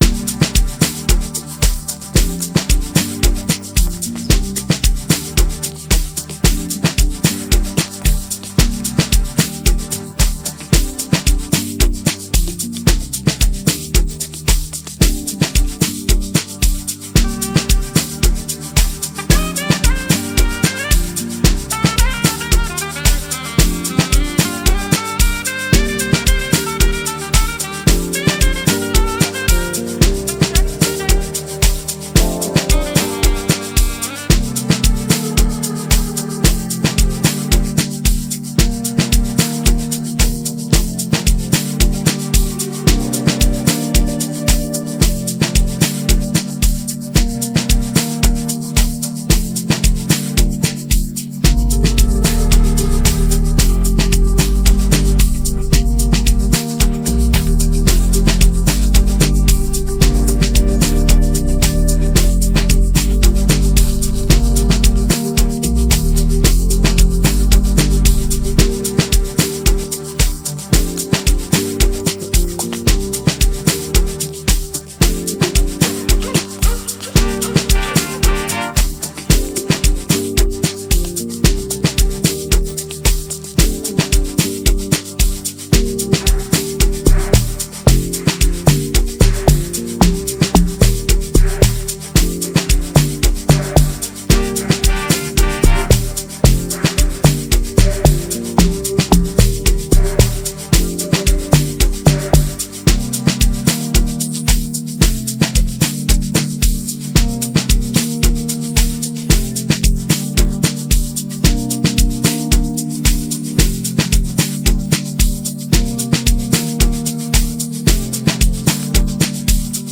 Afro popAfrobeats